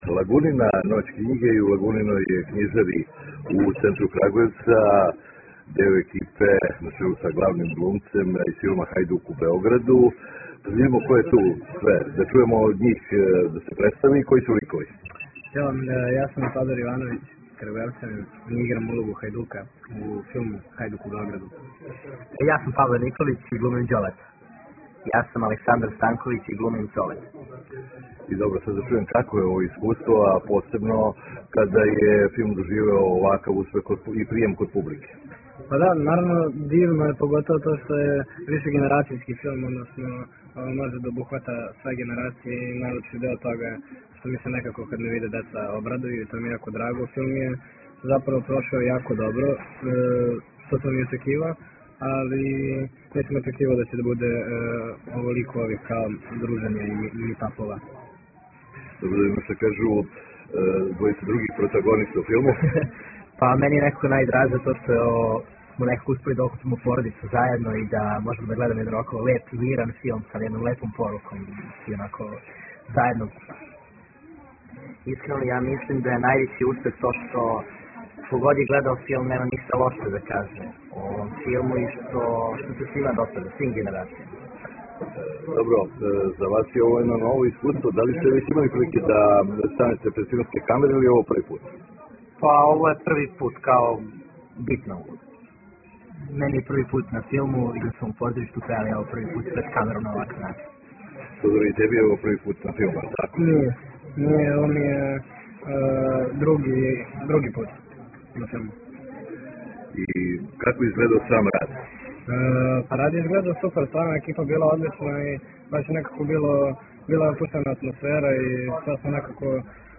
Прве вечери овогодишње Ноћи књиге у књижари „Лагуна“ у Крагујевцу са посетиоцима се дружио део екипе филма.